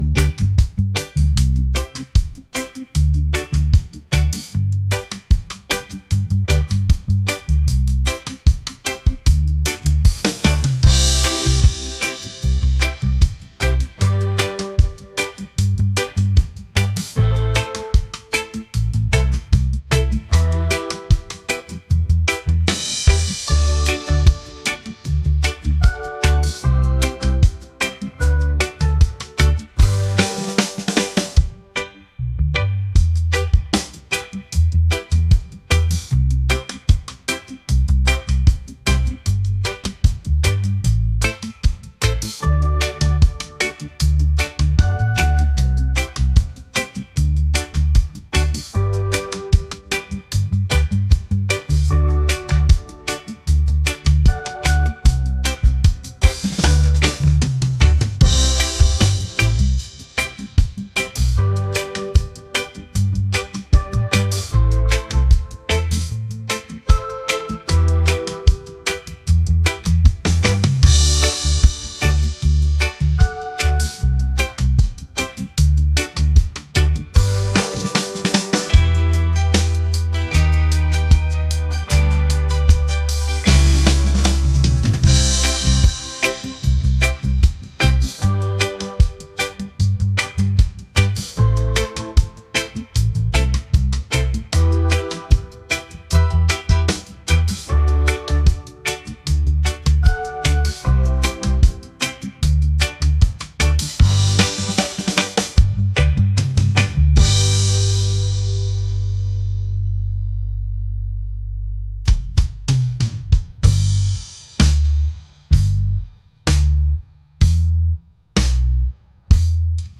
reggae | soul | upbeat